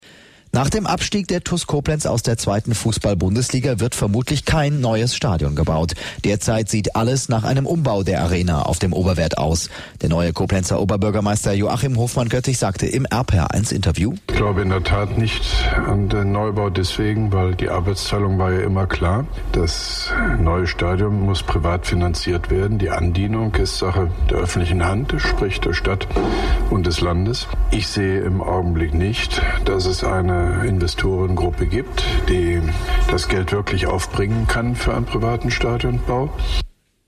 Kurzfassung Rundfunkinterview mit dem Koblenzer OB Joachim Hofmann-Göttig zum Thema TuS-Stadion